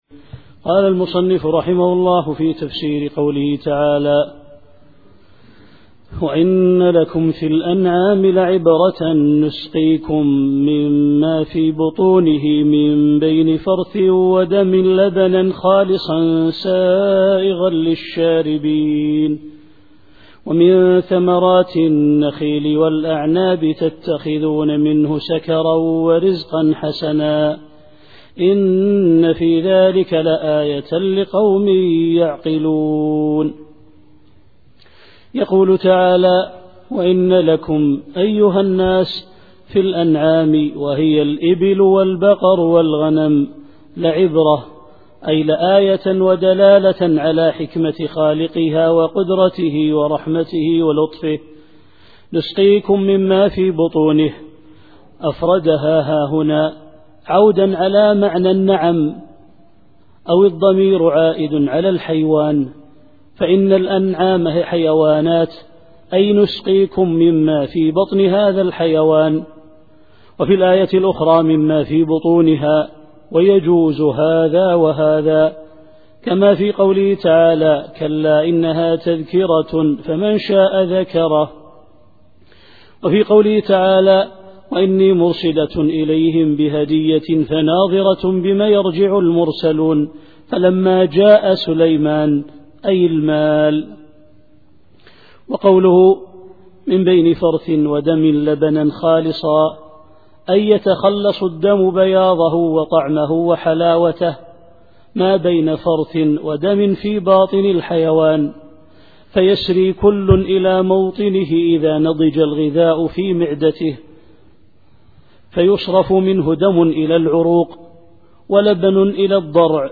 التفسير الصوتي [النحل / 67]